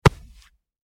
دانلود آهنگ دعوا 5 از افکت صوتی انسان و موجودات زنده
دانلود صدای دعوای 5 از ساعد نیوز با لینک مستقیم و کیفیت بالا
جلوه های صوتی